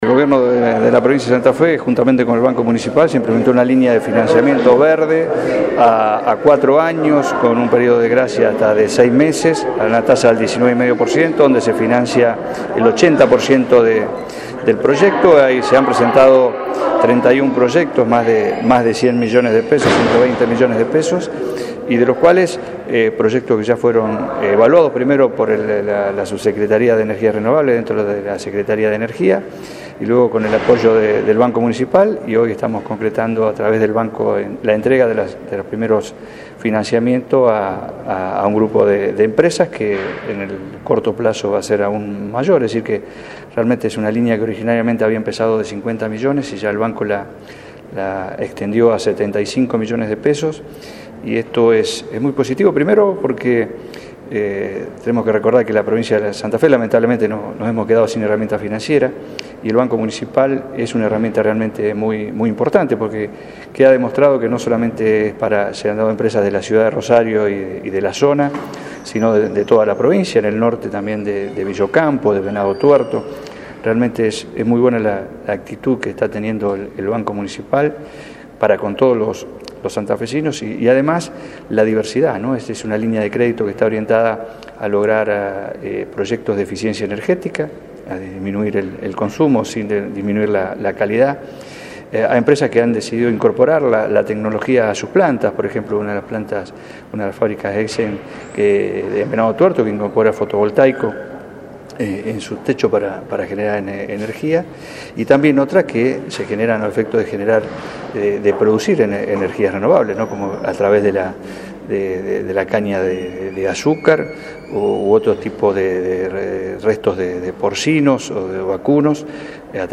Declaraciones del secretario de Estado de Energía, Jorge Álvarez.